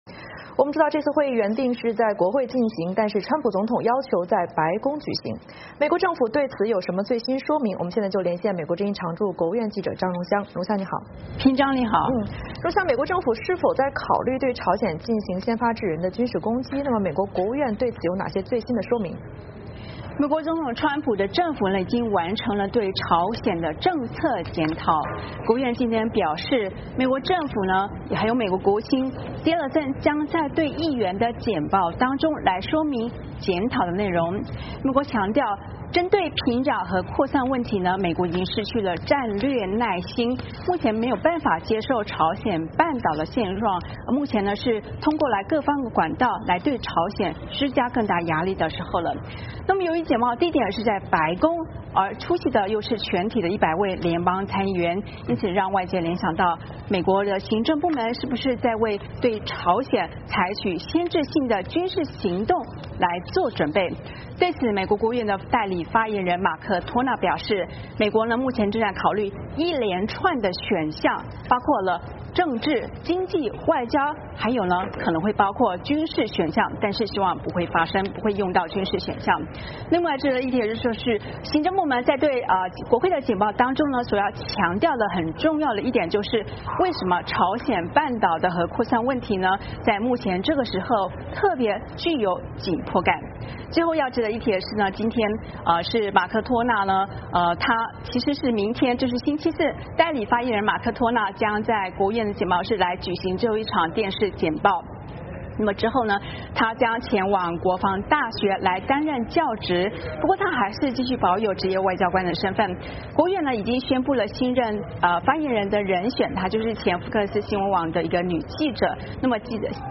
VOA连线：全体联邦参议员在白宫听取朝鲜问题简报